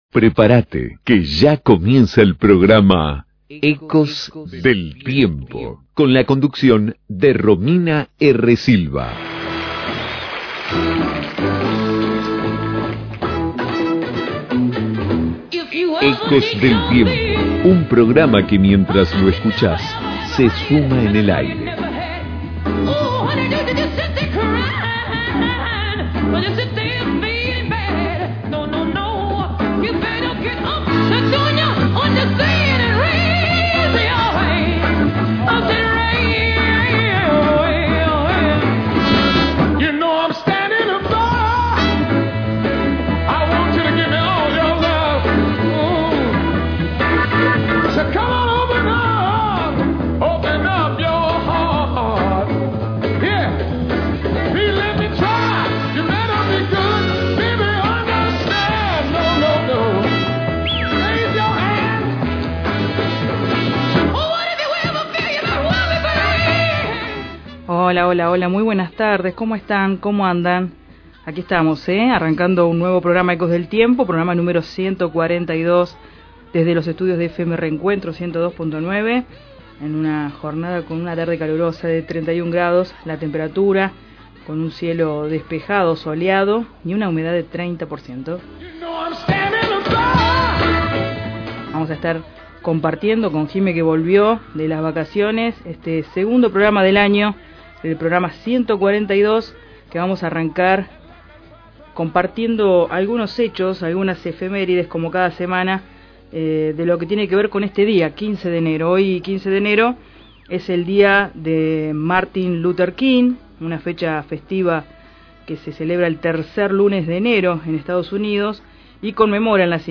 En vivo